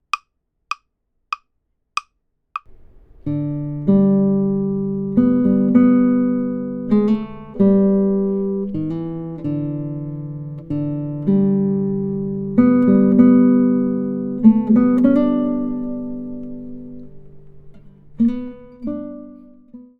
The pickup note leads into the downbeat and should be played slightly softer than the downbeat.
Amazing Grace | First two phrases (lines)
Amazing_grace_melody_2_phrases.mp3